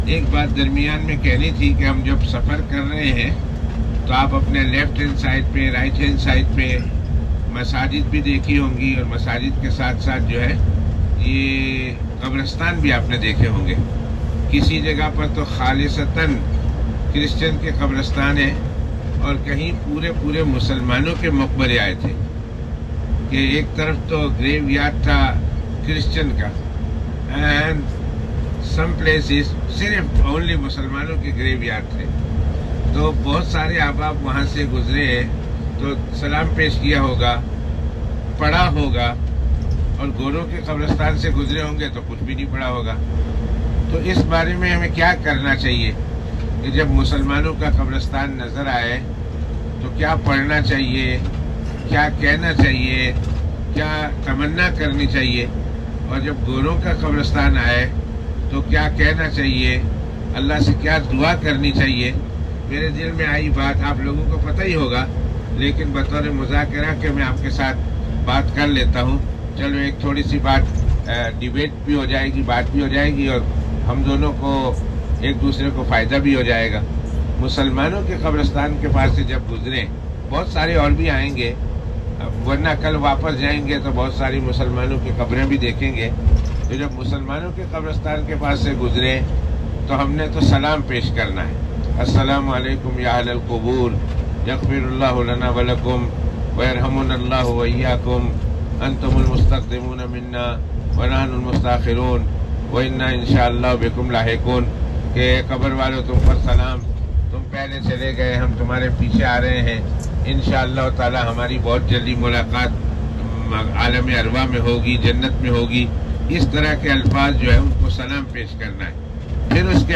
As we were travelling in Albania and North Macedonia, we passed by cemeteries where Muslims and non-Muslims were buried.